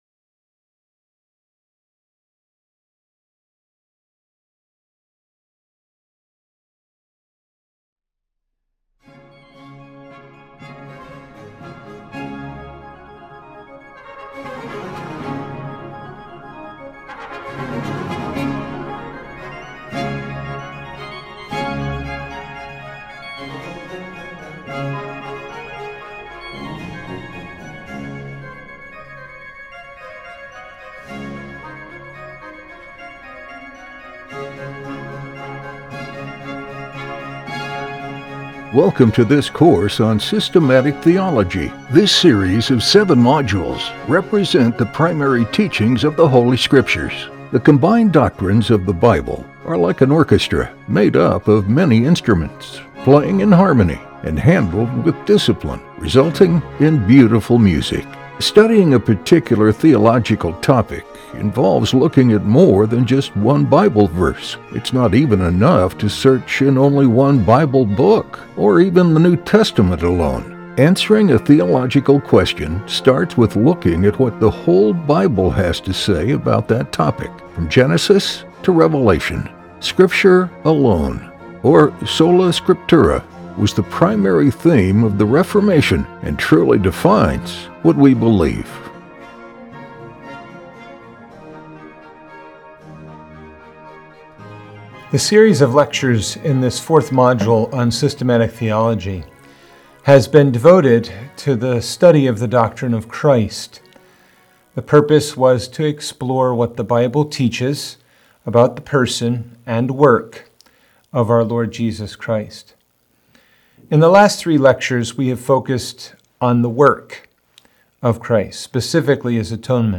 In this last and final lecture of this module, we will explore the Preeminence of Christ. Christ has the preeminence, which means he is superior to all others.